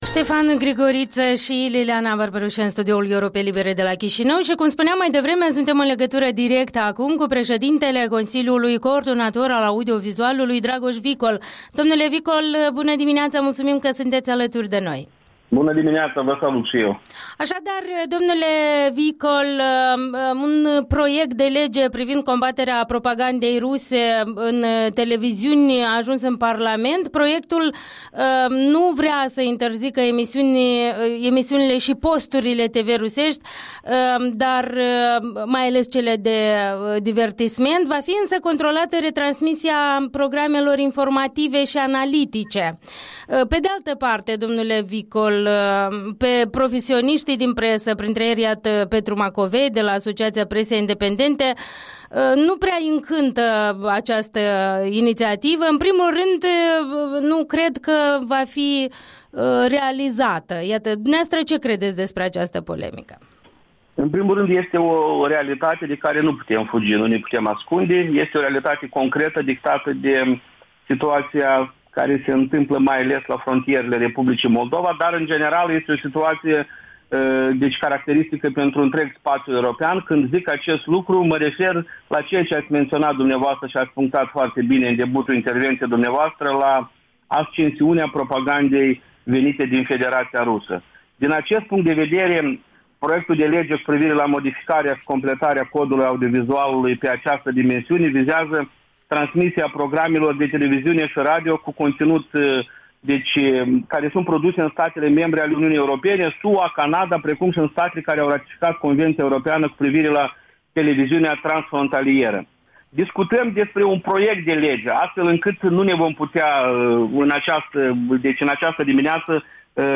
Interviul dimineții cu preşedintele Consiliului Coordonator al Audiovizualului.